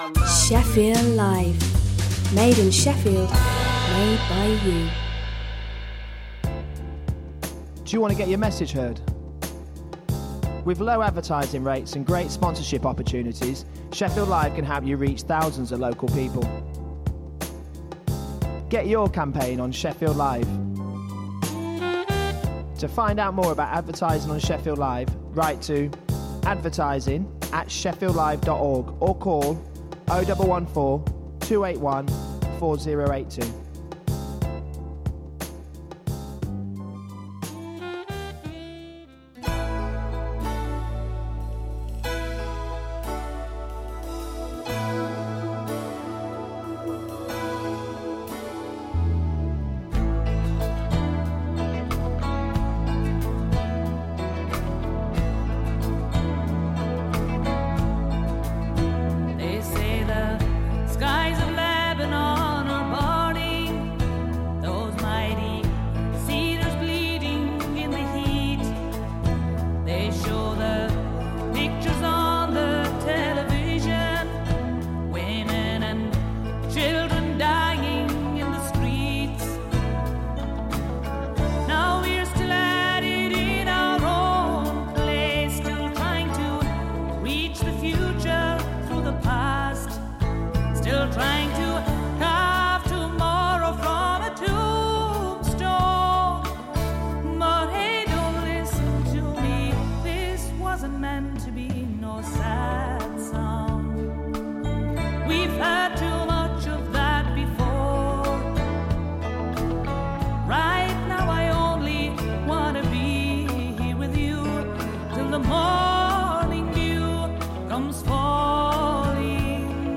Playing the very best in urban music, new & old…